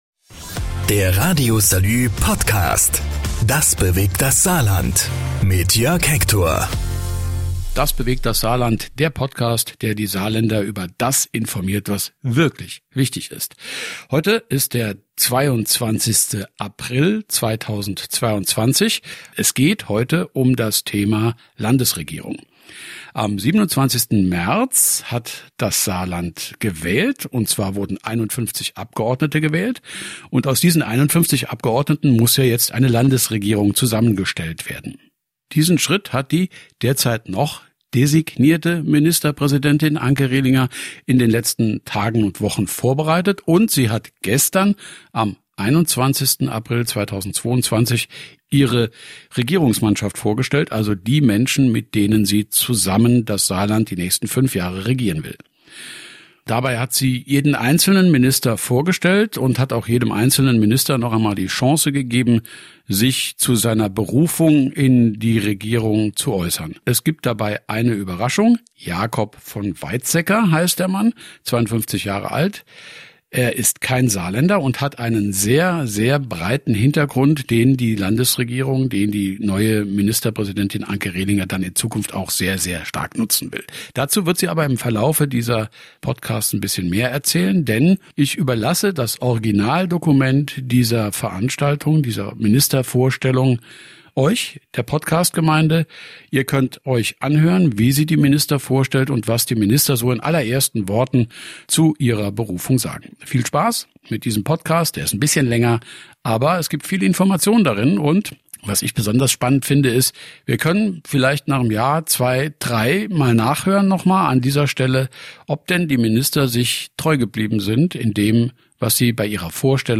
Diese Frage hat die Parteichefin und Kandidatin für das Amt der Ministerpräsidentin in diesem Podcast beantwortet. Weil die Vorstellung der Ministerriege öffentlich war, habe ich mich entschieden diesen Termin unbearbeitet zu veröffentlichen.